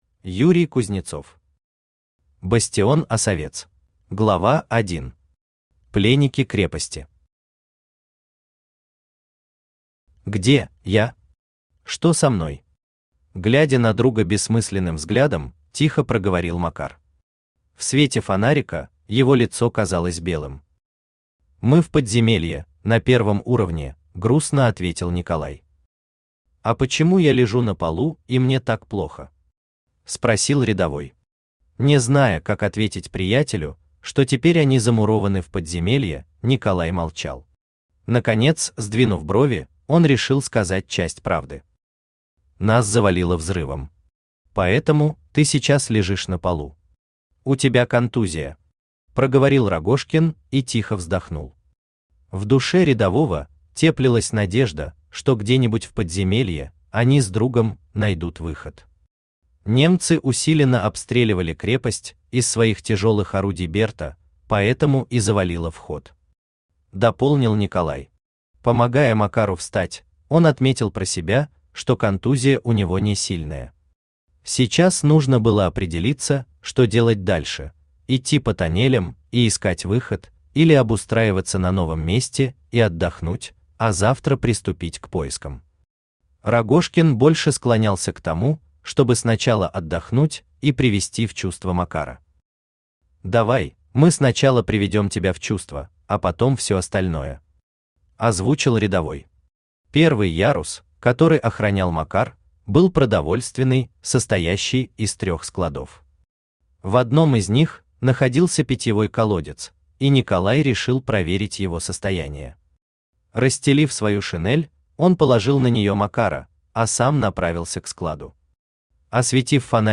Аудиокнига Бастион Осовец | Библиотека аудиокниг
Aудиокнига Бастион Осовец Автор Юрий Юрьевич Кузнецов Читает аудиокнигу Авточтец ЛитРес.